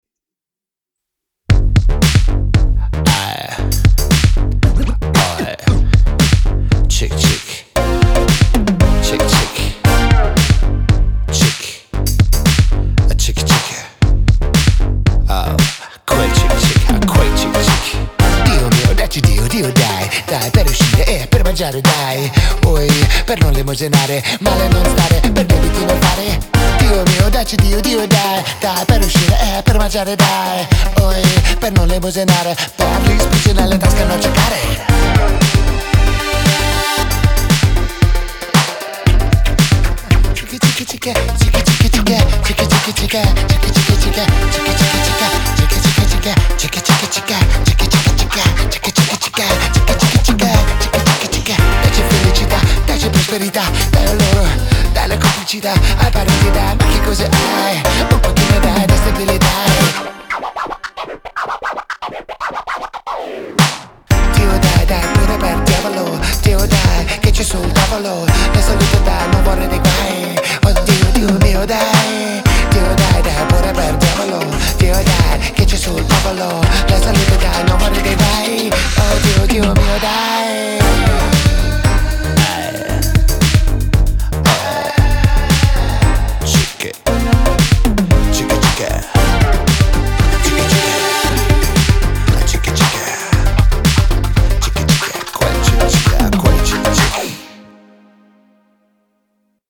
яркая и энергичная композиция